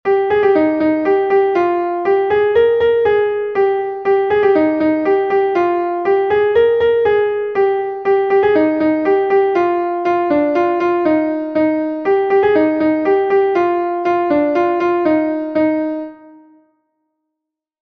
Gavotenn Er Gemene is a Gavotte from Brittany